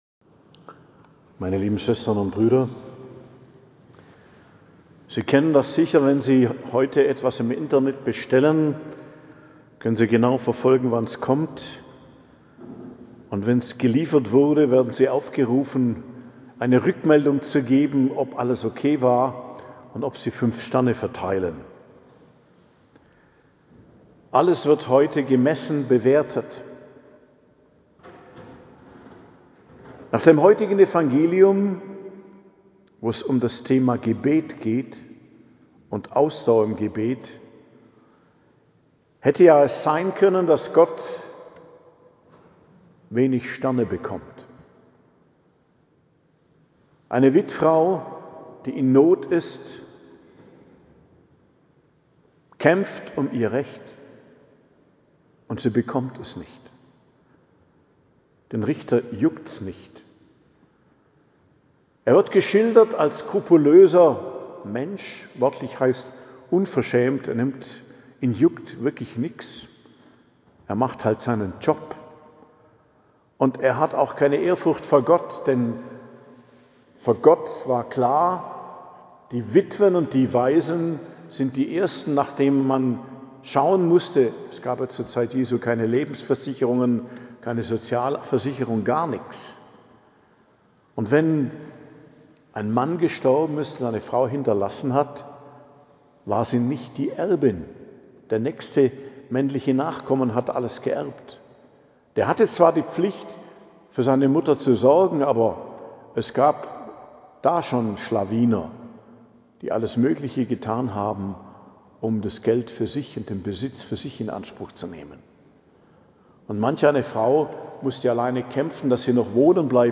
Predigt zum 29. Sonntag i.J., 19.10.2025 ~ Geistliches Zentrum Kloster Heiligkreuztal Podcast